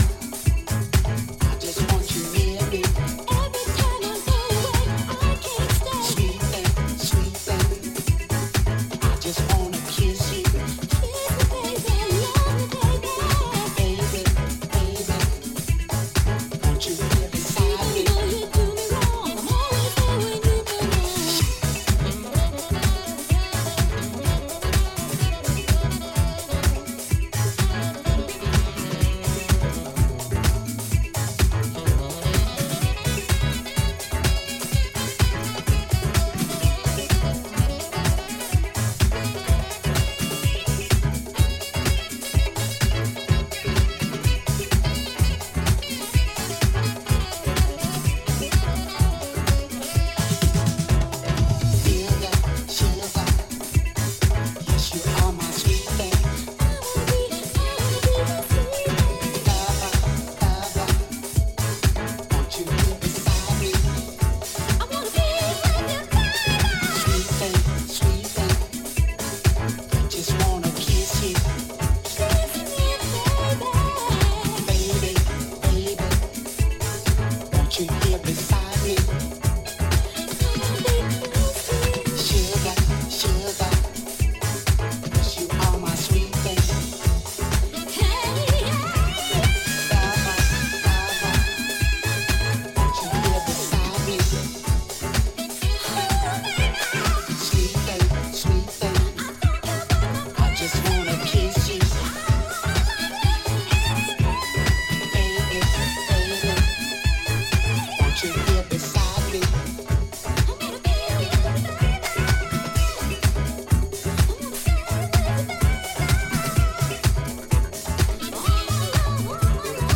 rooted in soul, jazz and groove.
signature flair for rhythmic depth and dancefloor flow